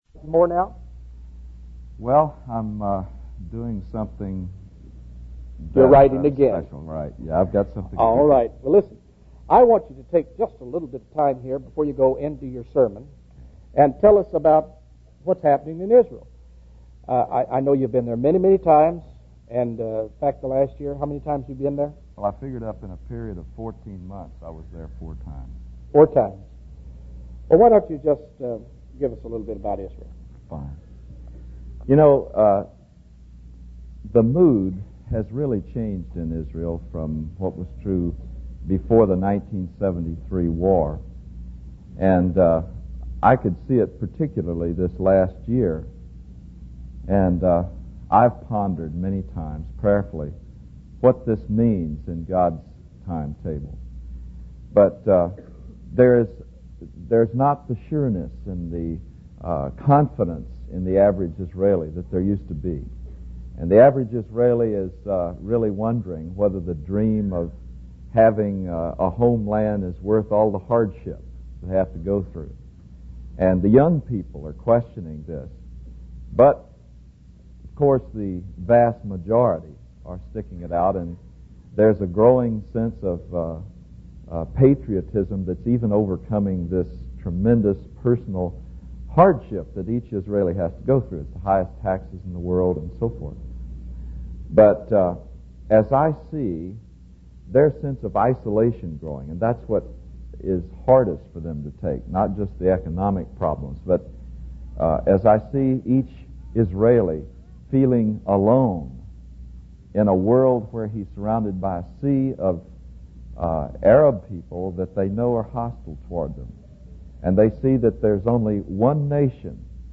He urges listeners to ensure their faith is rooted in a personal relationship with Jesus, as this is the only way to avoid condemnation.